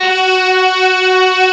FLSTRINGS1F4.wav